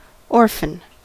Ääntäminen
Vaihtoehtoiset kirjoitusmuodot (rikkinäinen englanti) orphing Ääntäminen US : IPA : [ˈɔr.fən] Tuntematon aksentti: IPA : /ɔː(r)fən/ Haettu sana löytyi näillä lähdekielillä: englanti Käännös Konteksti Substantiivit 1.